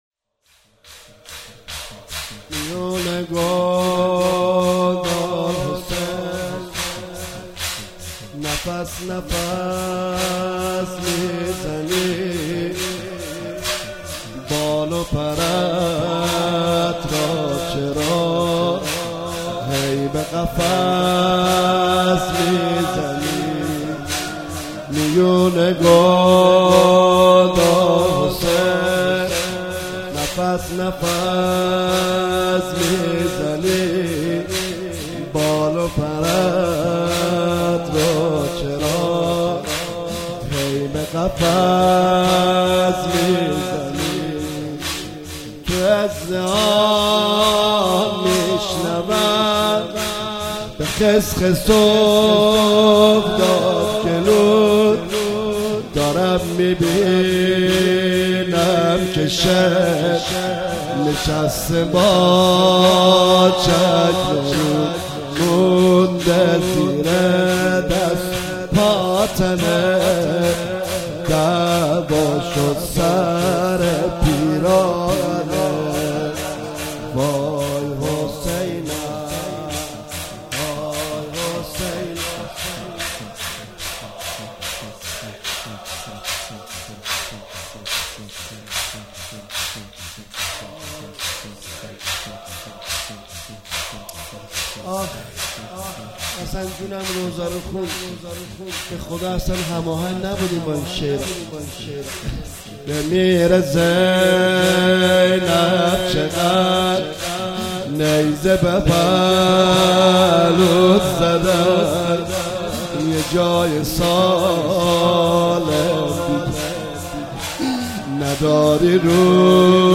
10 اسفند 97 - هیئت مکتب الحسین - شور - میون گودال حسین